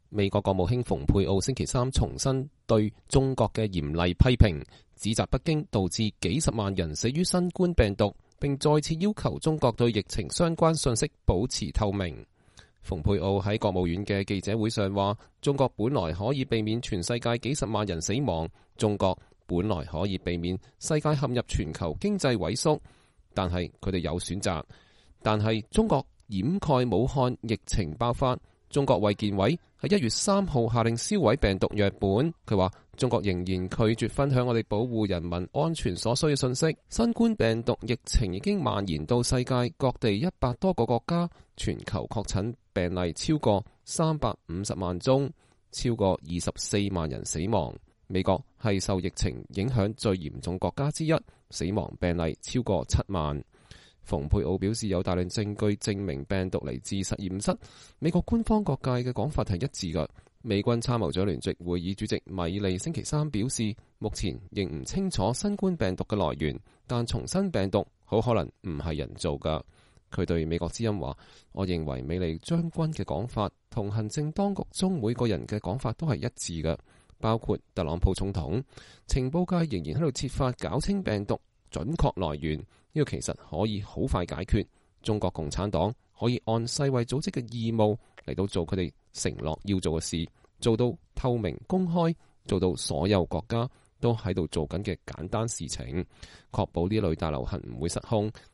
美國國務卿蓬佩奧在國務院舉行的記者會上談論新冠病毒疫情。(2020年5月6日)